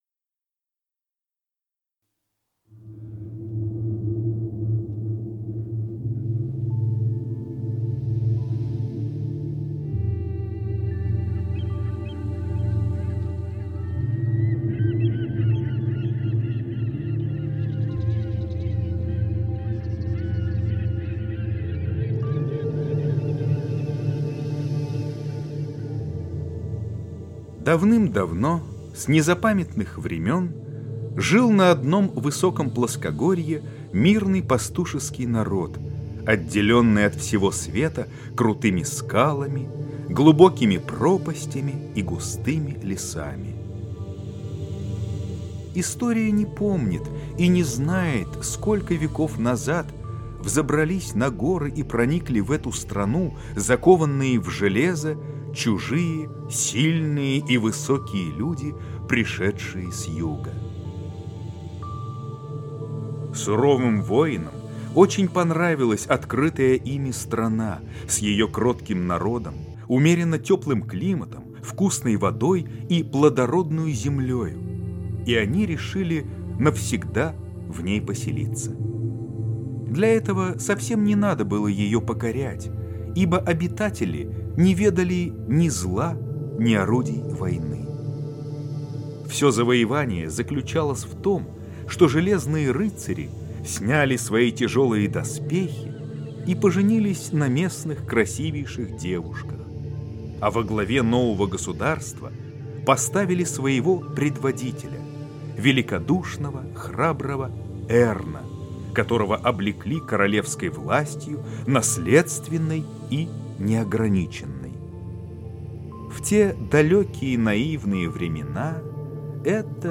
Синяя звезда - аудио рассказ Куприна А.И. Мирный пастушеский народ жил отделенный от всего света крутыми скалами...